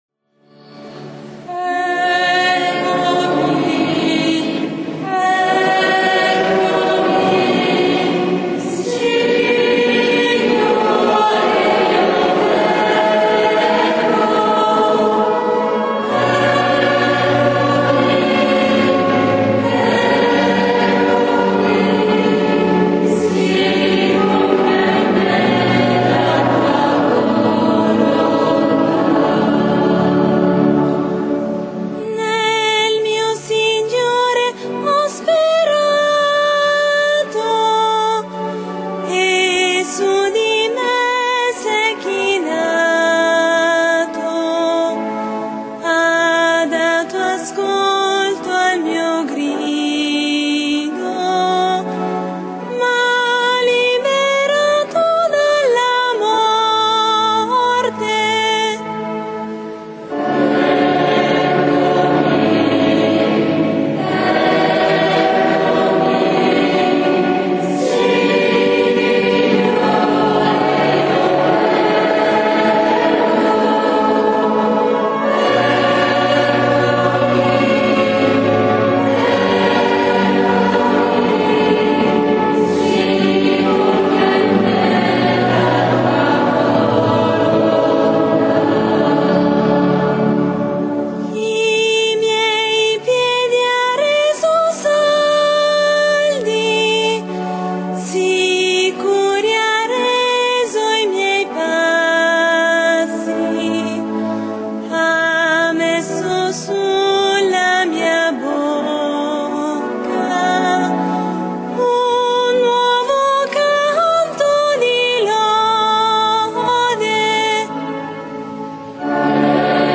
Celebrazione della Cena del Signore